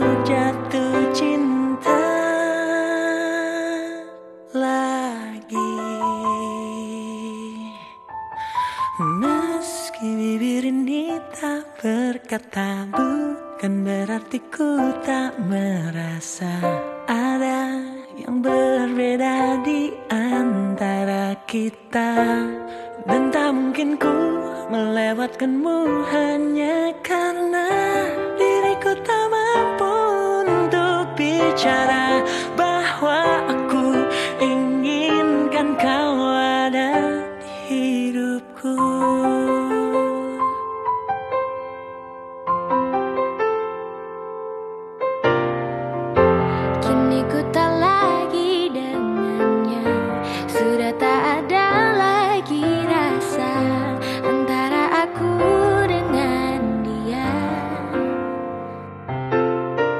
Cuplikan live semalam
suara bikin baper banyak orang